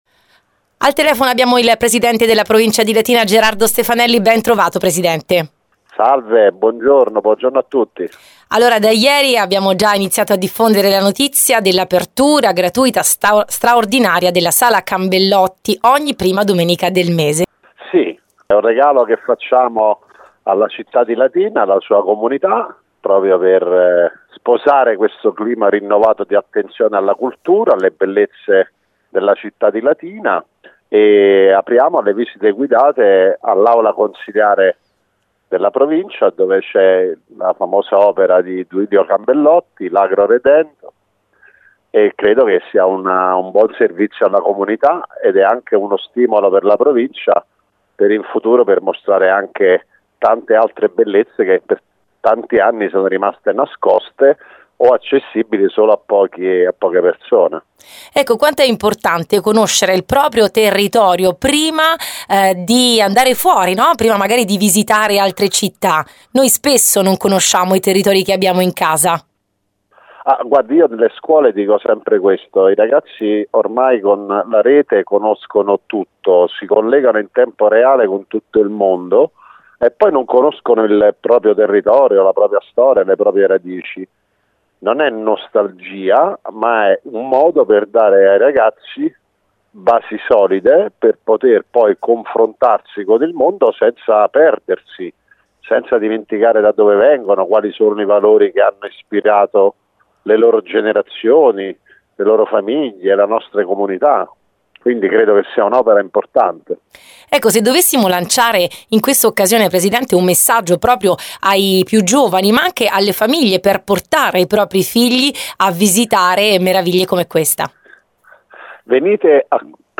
Ascolta l’intervista integrale al Presidente della Provincia di Latina Gerardo Stefanelli.